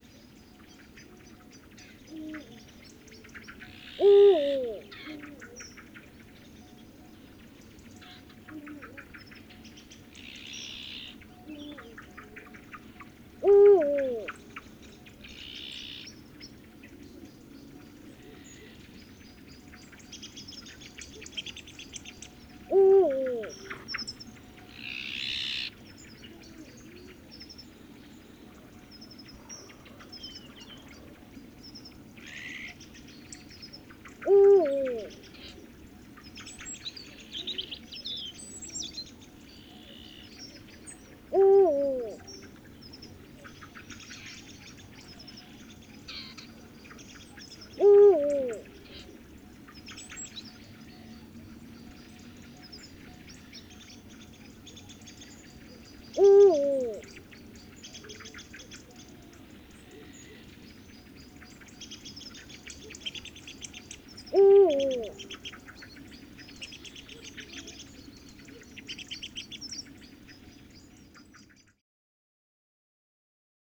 Eurasian Eagle-owl
At dusk when I recorded CD3-32, I was amazed to hear up to four males and one very distant female calling in the space of just two minutes.
Hooting of four males and one female.